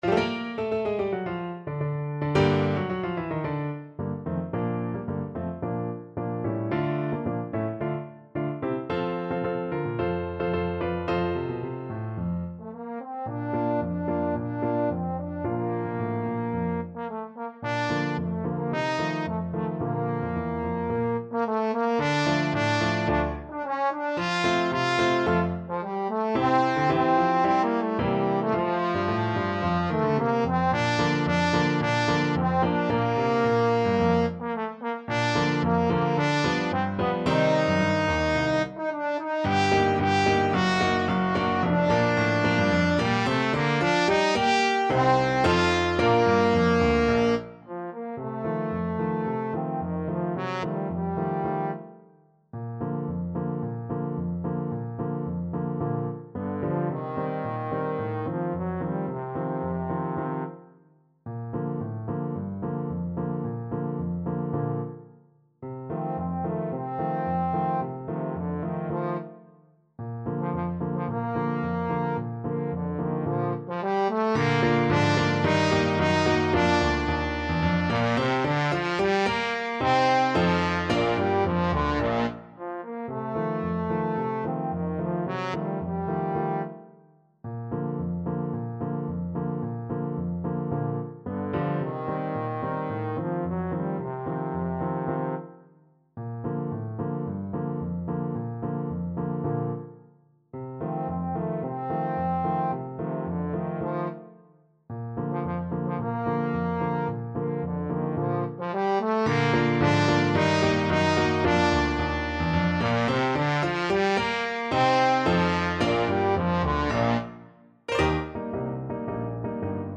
Classical (View more Classical Trumpet-Trombone Duet Music)